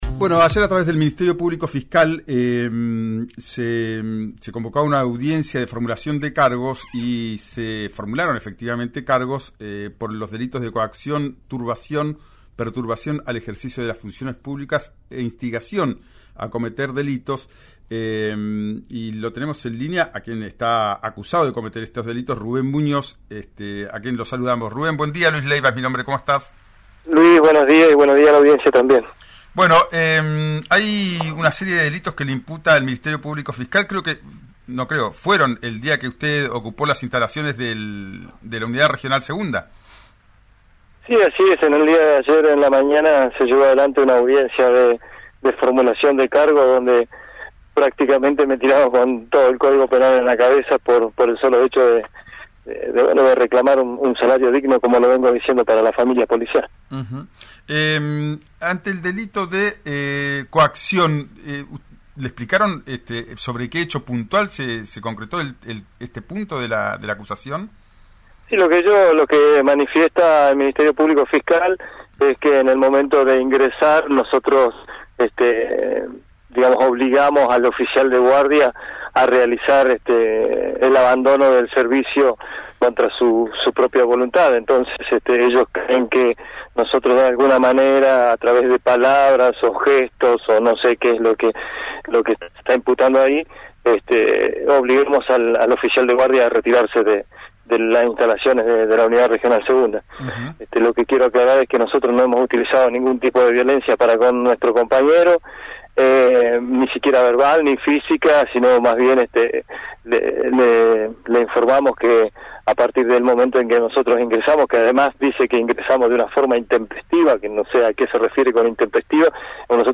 en «Ya es tiempo» por RÍO NEGRO RADIO: